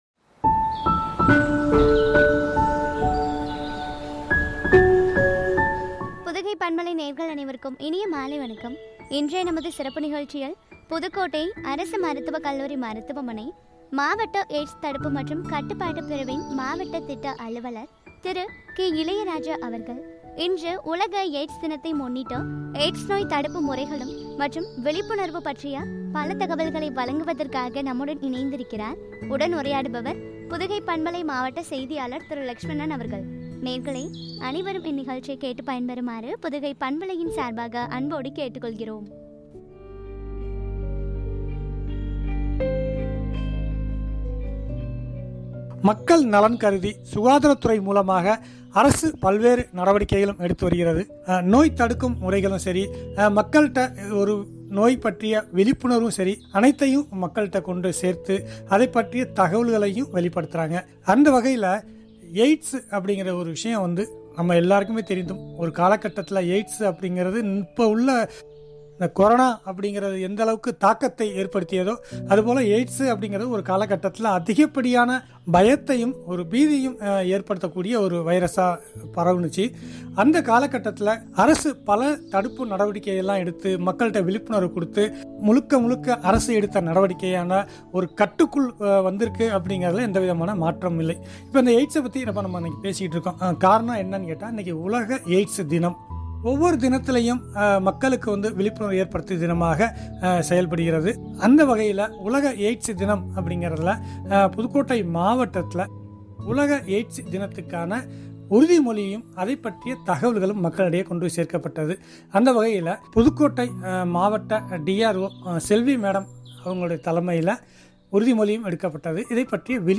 எய்ட்ஸ் நோய் தடுப்பு முறைகள் மற்றும் விழிப்புணர்வு பற்றிய உரையாடல்.